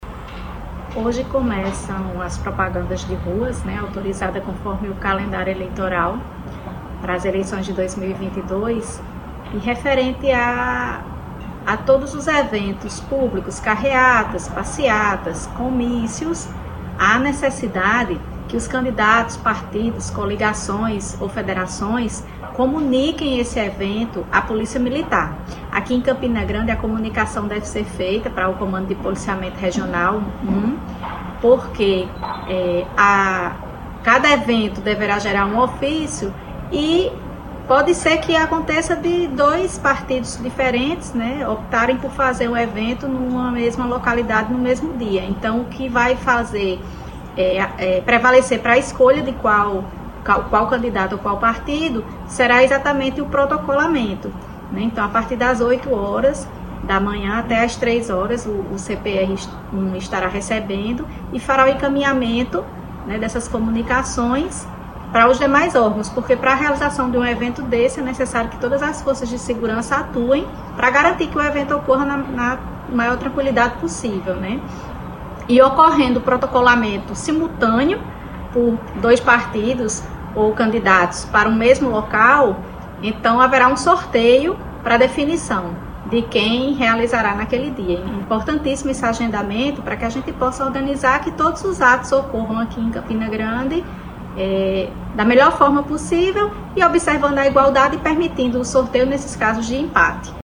Os comentários da magistrada foram registrados pelo programa Correio Debate, da 98 FM, de João Pessoa, nesta terça-feira (16/08).